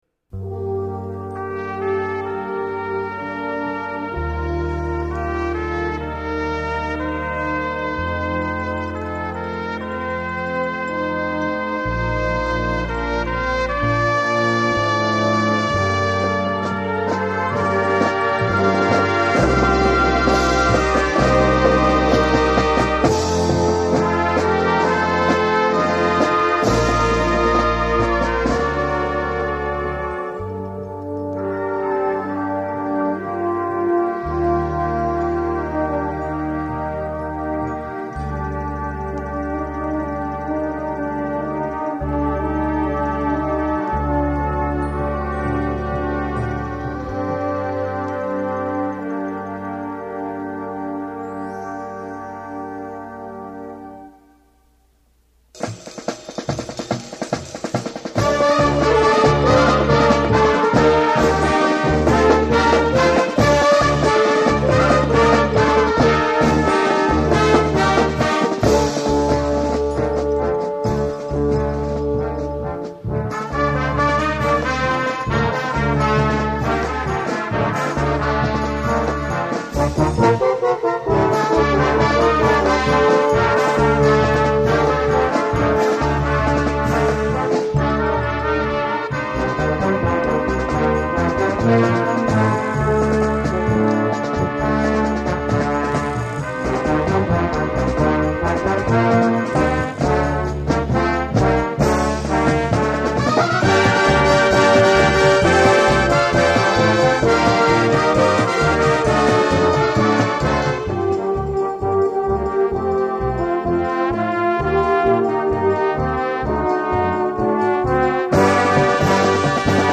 Marching Band Field